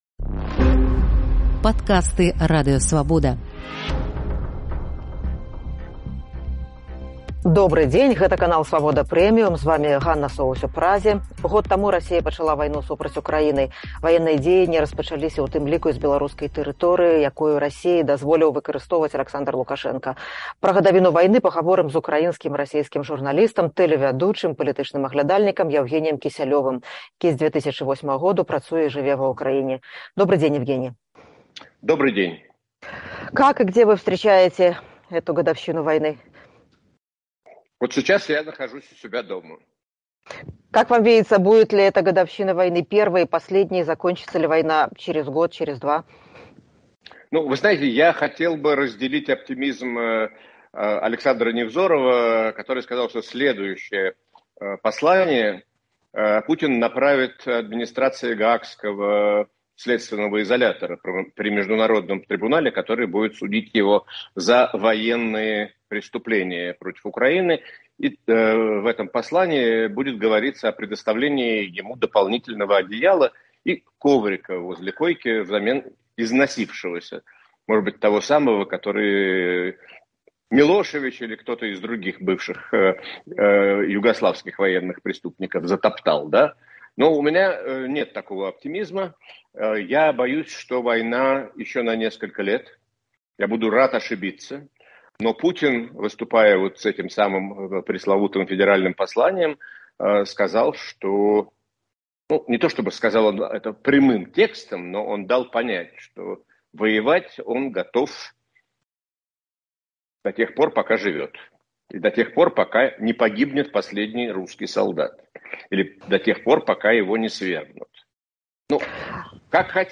Пра гадавіну вайны Расеі супраць Украіны, віну і адказнасьць расейцаў, галасаваньне ў ААН супраць вываду войскаў і шанцы Беларусі на вызваленьне гаворым з украінскім і расейскім журналістам, тэлевядоўцам, палітычным аглядальнікам, былым кіраўніком тэлеканалу НТВ Яўгеніем Кісялёвым.